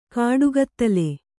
♪ kāḍu gattale